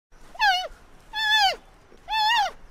Panda_Suara.ogg